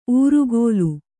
♪ ūrugōlu